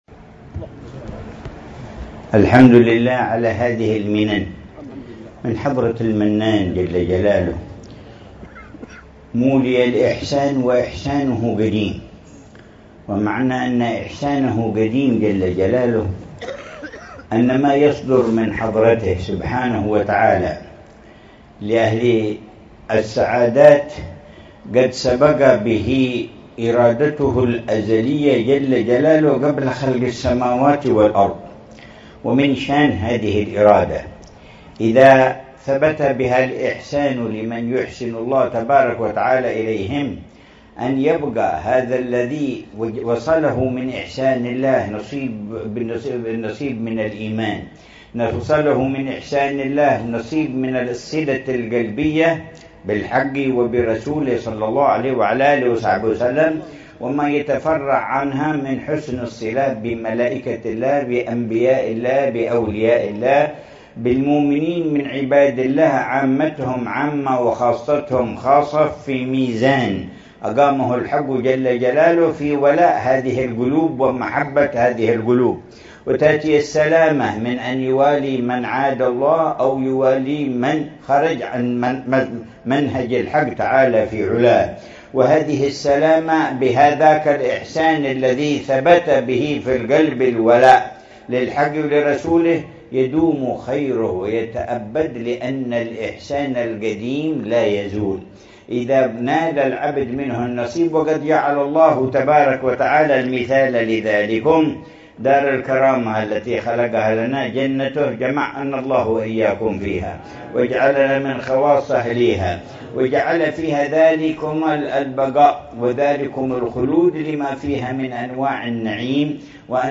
في مجلس الذكر والتذكير